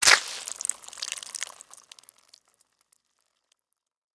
На этой странице вы найдете коллекцию звуков укуса — резких, комичных и ярких, как в мультфильмах.
Укус вампира с кровавыми брызгами